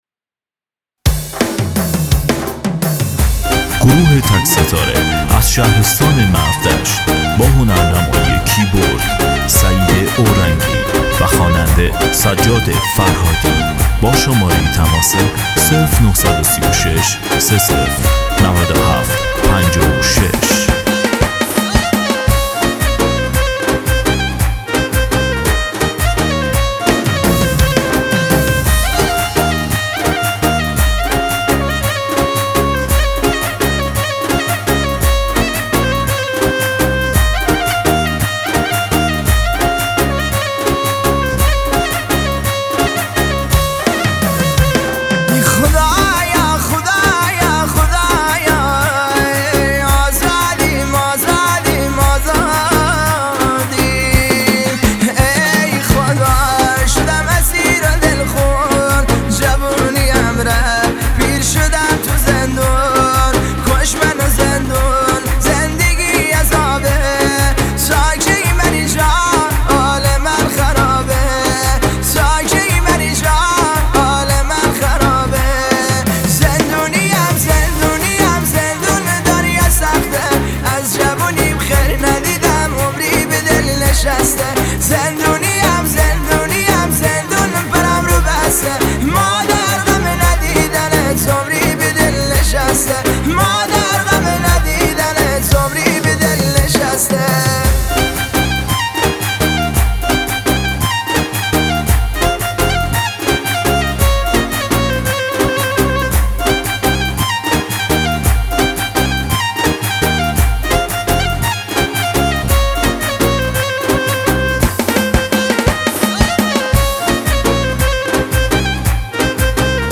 آهنگ محلی غمگین و معروف